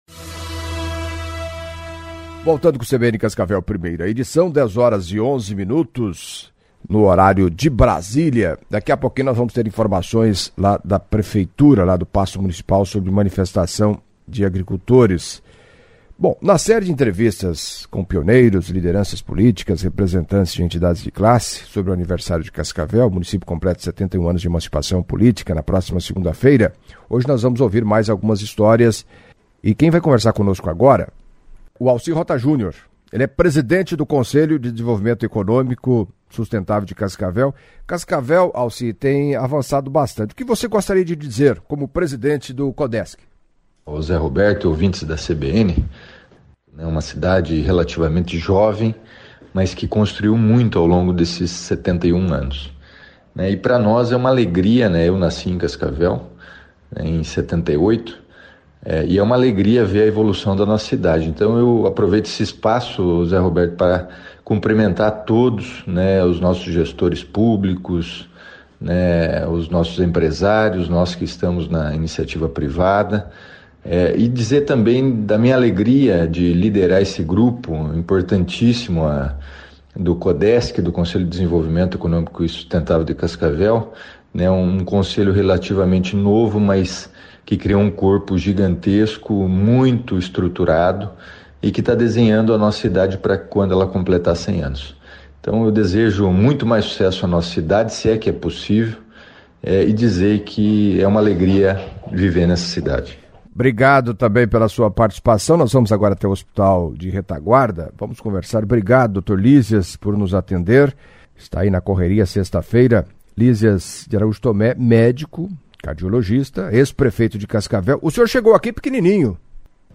A CBN segue ouvindo pioneiros, lideranças políticas e representantes de entidades de classe, sobre o aniversário de Cascavel. O município completa na segunda-feira, 14 de novembro, 71 anos de emancipação política.